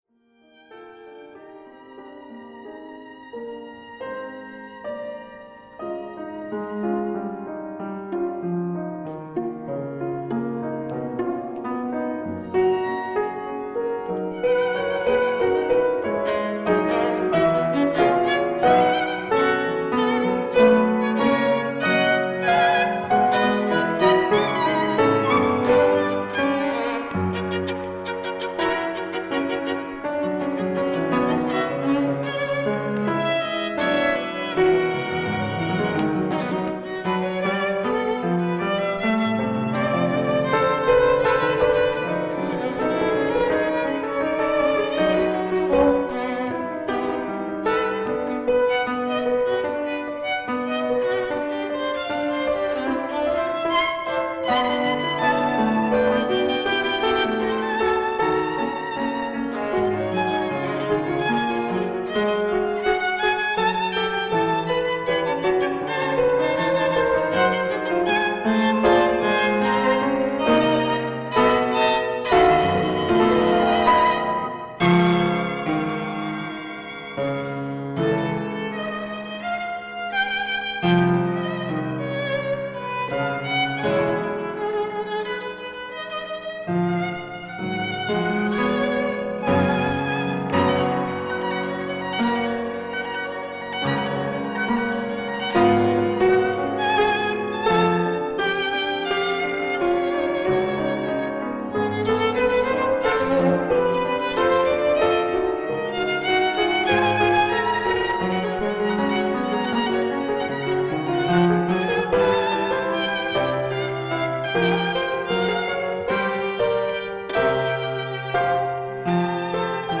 Piano 1:48 1.